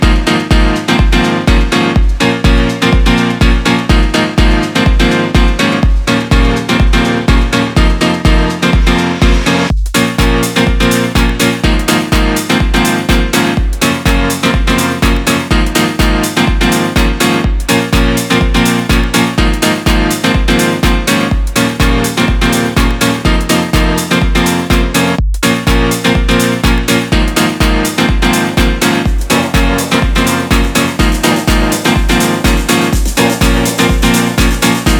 Жанр: Танцевальные
# Dance